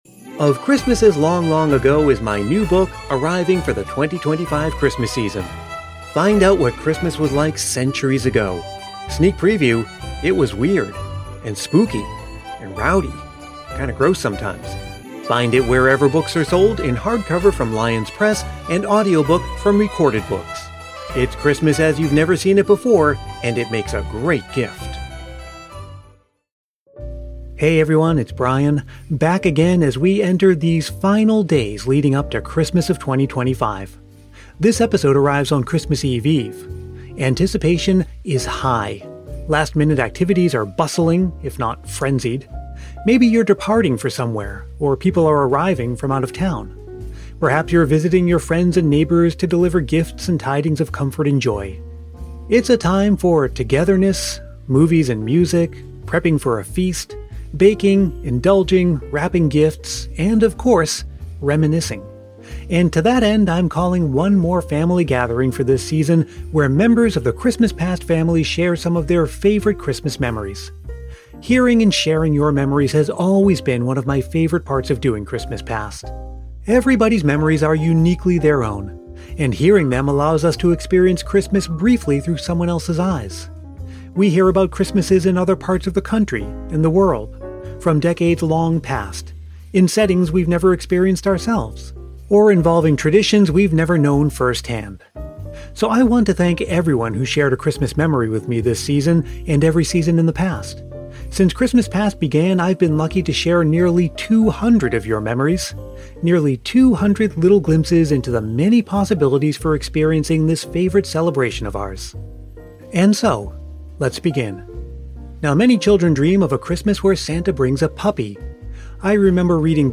On this episode, we bring you the the 5th Anniversary presentation of our made-for-podcast Christmas movie: “A Bomb For Christmas.”